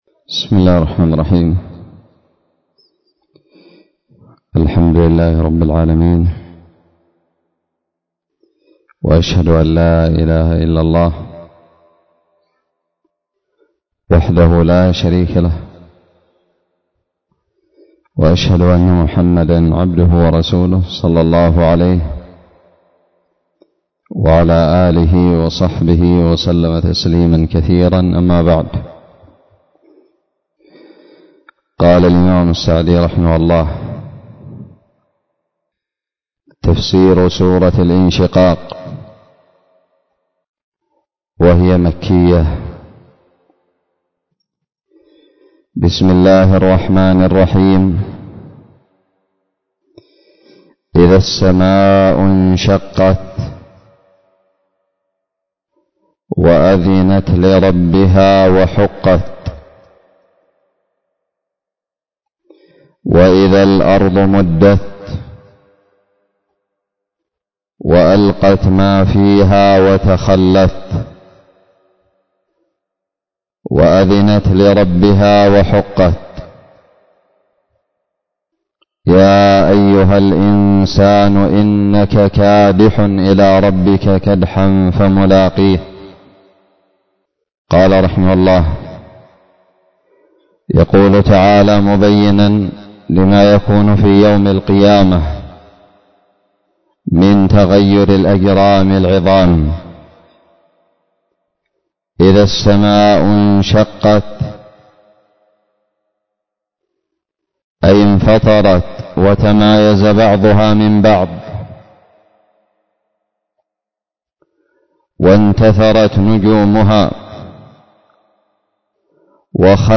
ألقيت بدار الحديث السلفية للعلوم الشرعية بالضالع